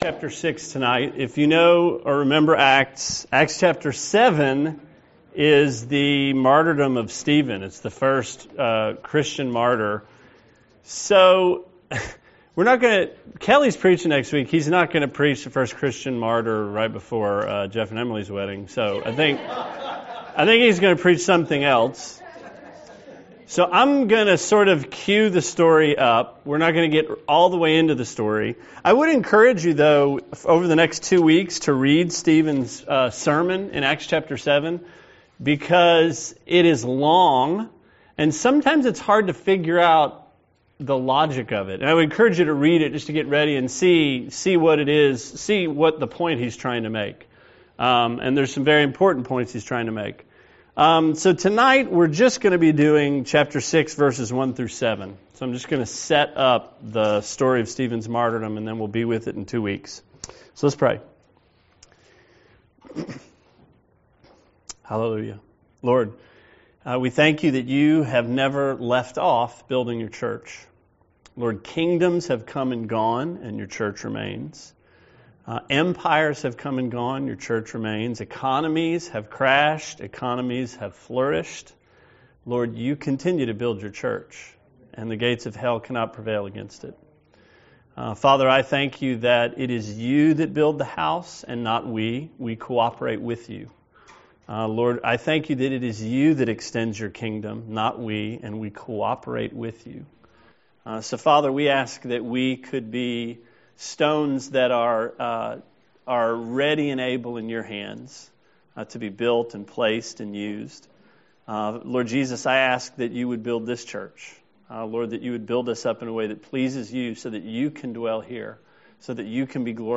Sermon 4/22: Acts 6:1-7